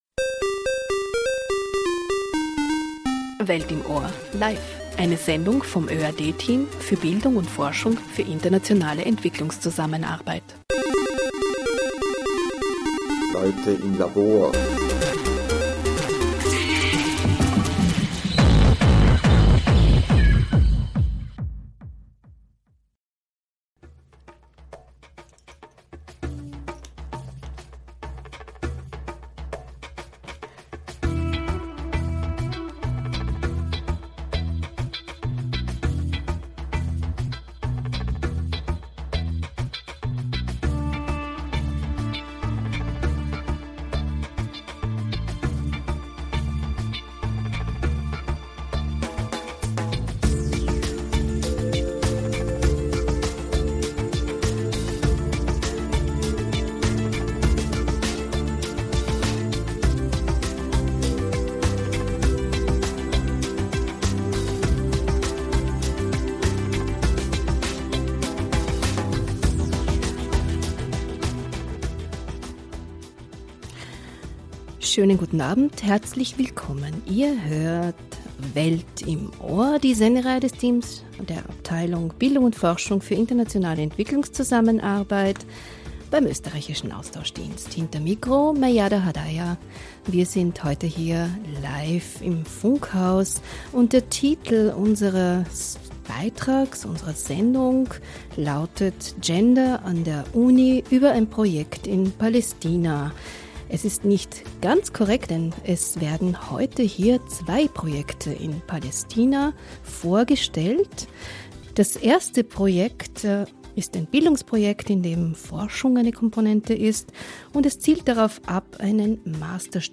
Wissenschafterinnen berichten über den Hochschulkontext und die Situation von Frauen im Gazastreifen.